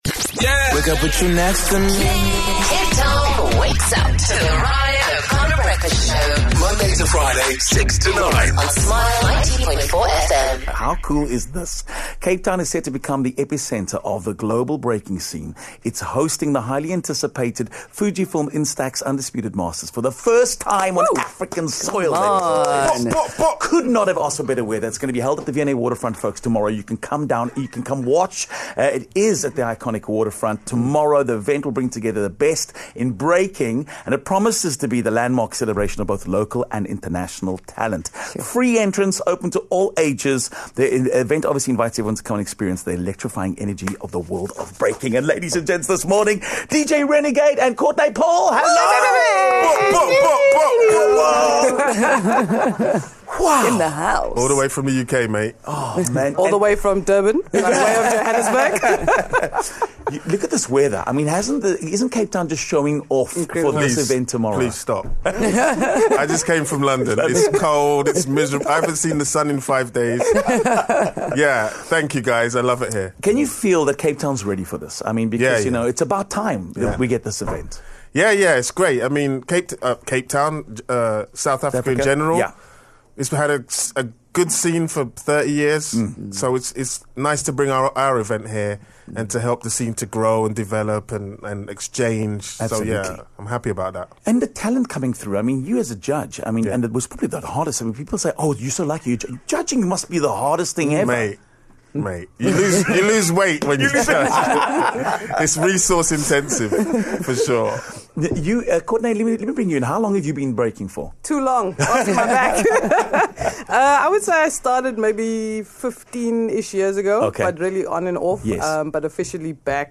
joined us in studio